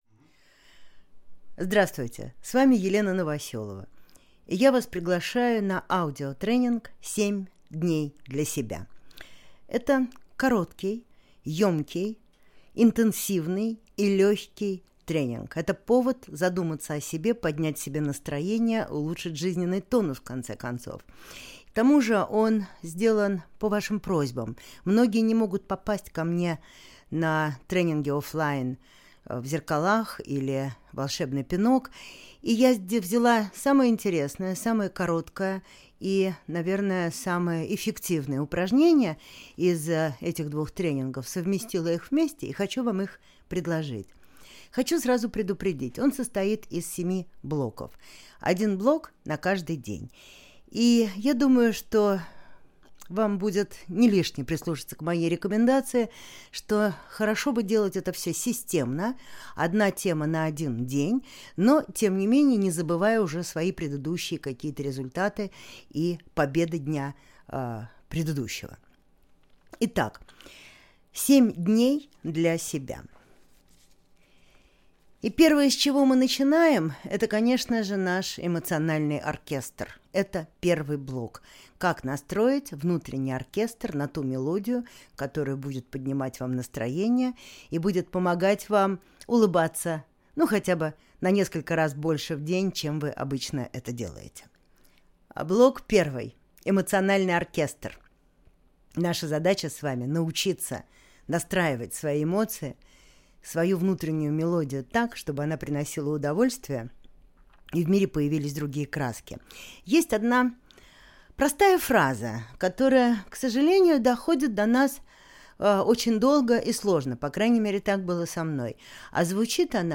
Аудиокнига 7 дней для себя. Аудиотренинг | Библиотека аудиокниг